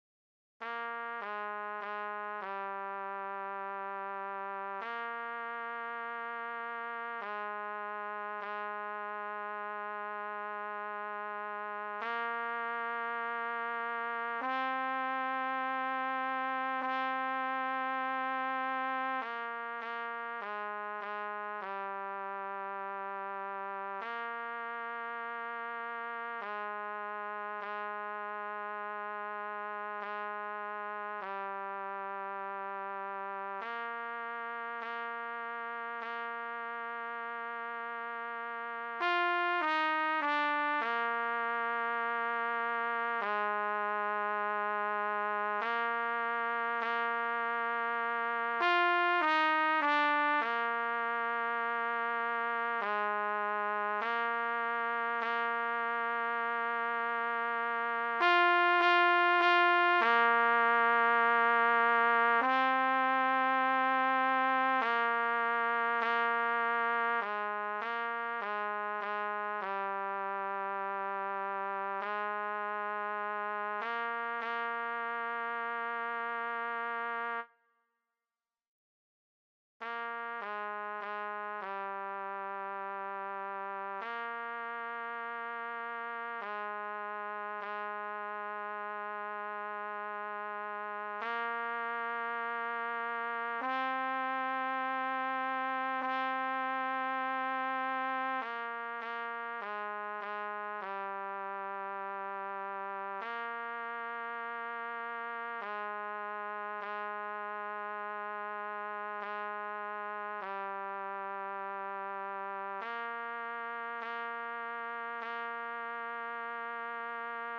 C=Harmony/Bass Part-for beginner players